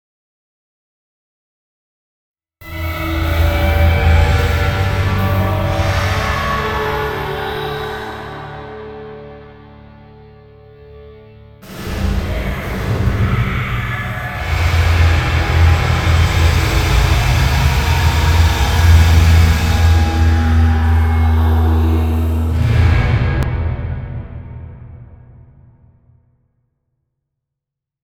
Reverb experiments